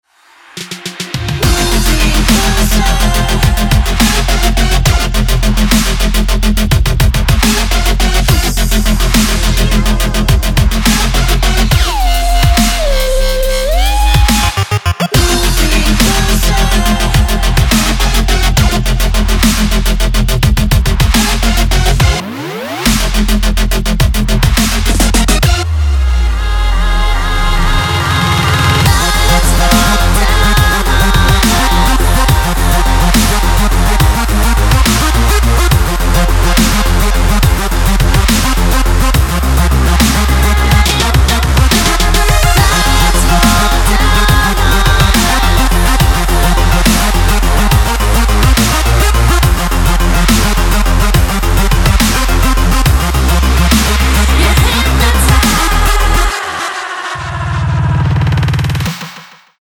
club музыка